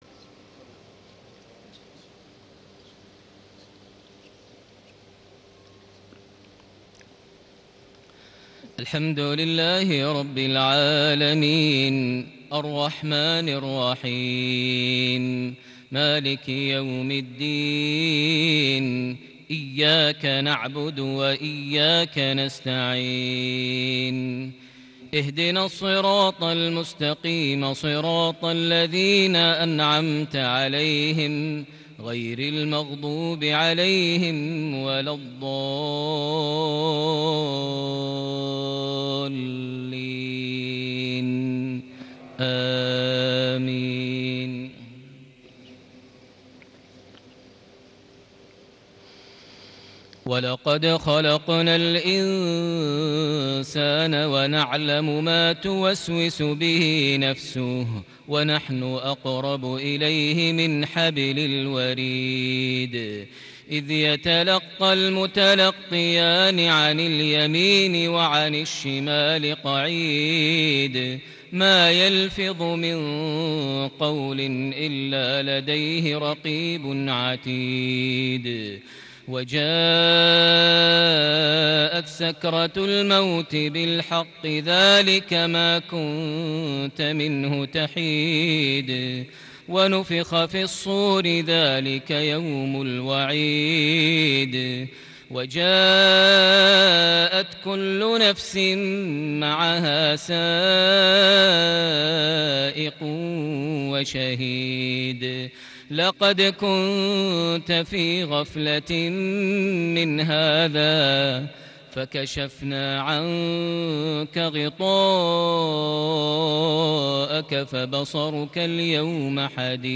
صلاة المغرب ٢ ربيع الآخر ١٤٣٨هـ سورة ق ١٦-٣٥ > 1438 هـ > الفروض - تلاوات ماهر المعيقلي